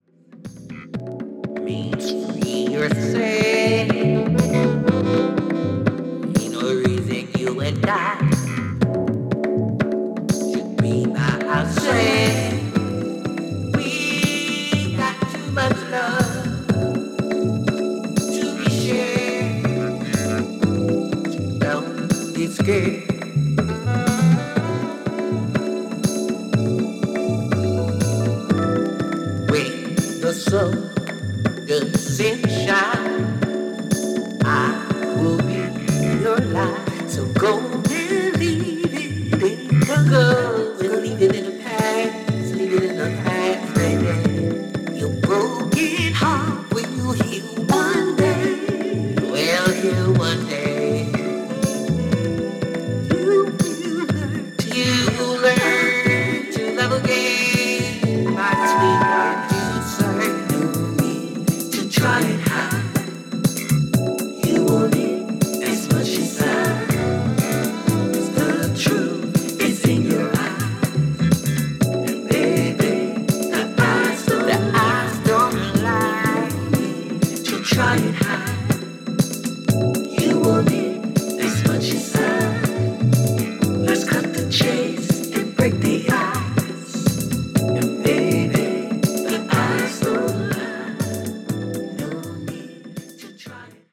(試聴音源録りました)